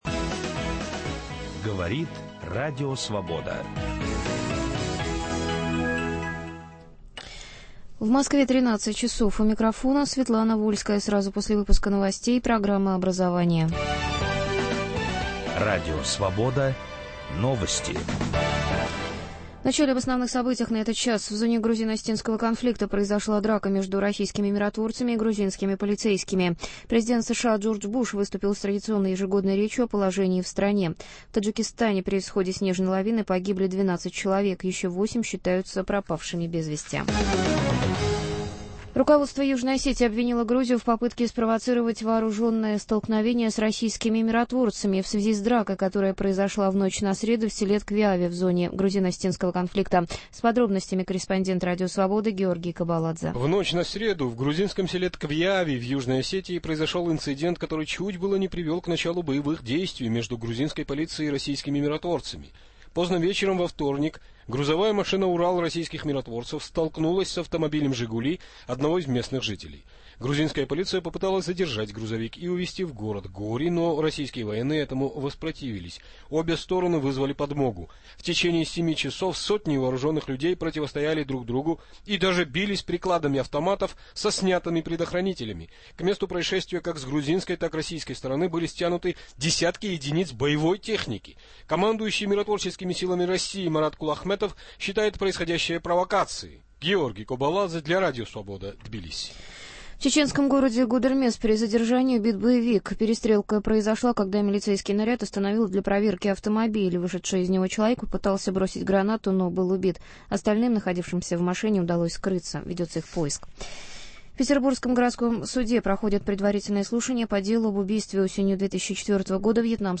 В прямом эфире вместе с экспертами слушатели обсуждают, что надо изменить и что стоит сохранить в российской системе образования, чтобы дети получали полноценные знания, а преподаватели - достойную зарплату.